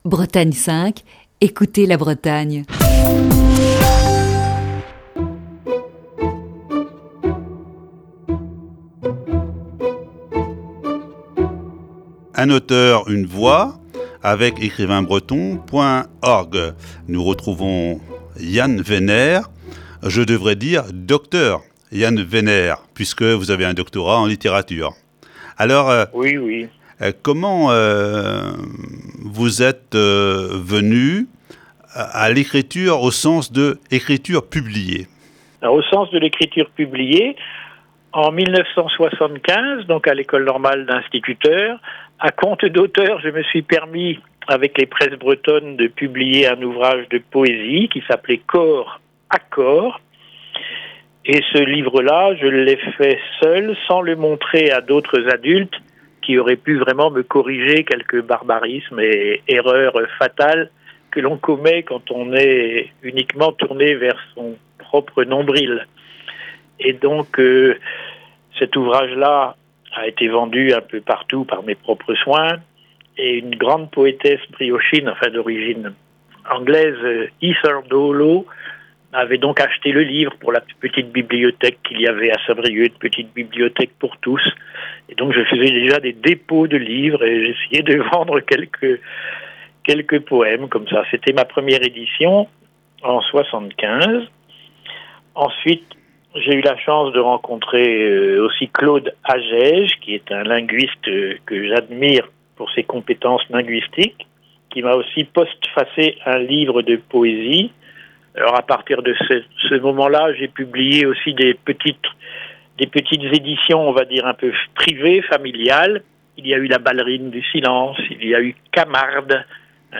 Voici ce mercredi, la troisième partie de cette série d'entretiens.